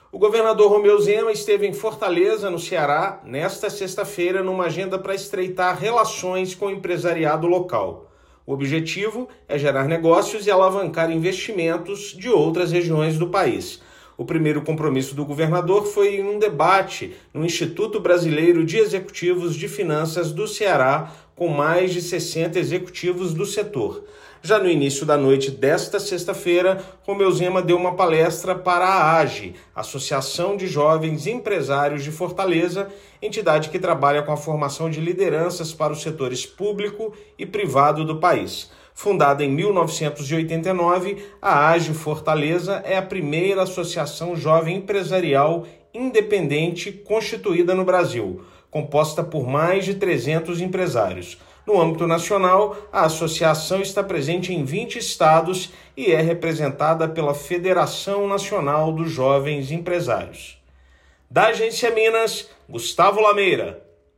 Agendas em Fortaleza buscam compartilhar ações desenvolvidas em Minas e firmar novas parcerias. Ouça matéria de rádio.